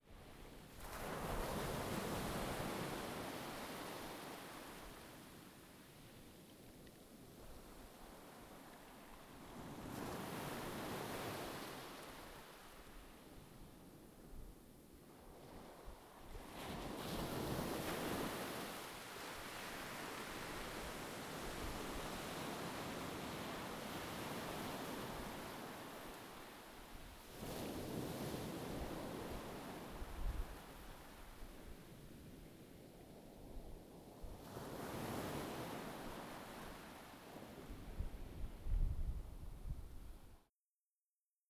beach-tide-amplified.wav